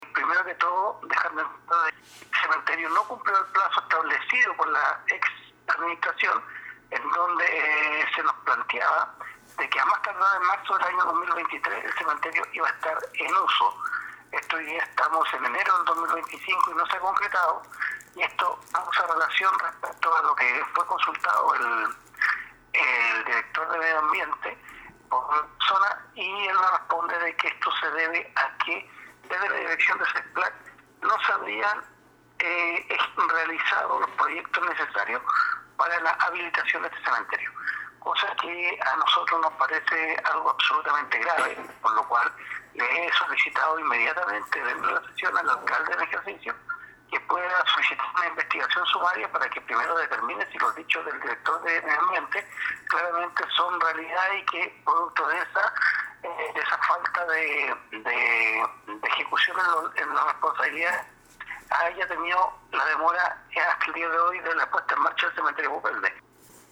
Ante todos estos hechos, el concejal de Ancud, Andrés Ibáñez, dijo que existen a su juicio, actuaciones irregulares, por lo que recomendó al alcalde Andrés Ojeda que proceda con una investigación sumaria que permita conocer por qué no se presentaron los proyectos que habrían permitido avanzar en tener un adecuado campo santo para la comuna.